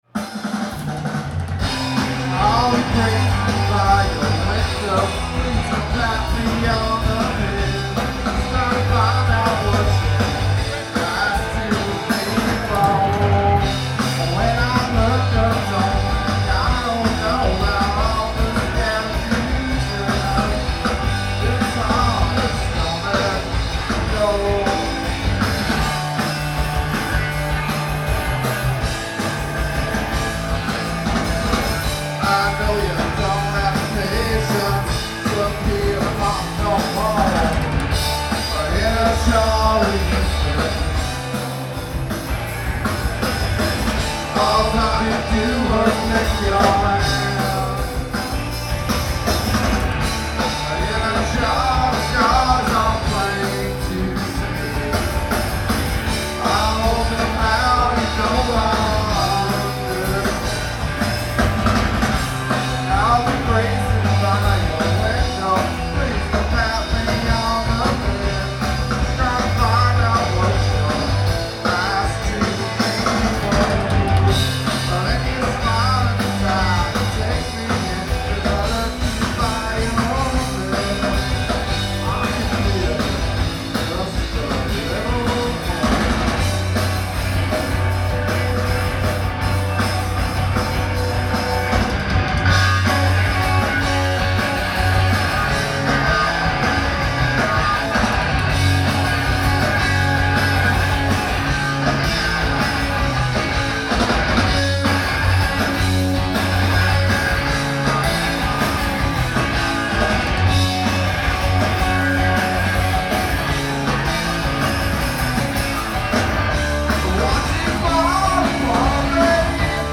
And boy did they.
live at Avalon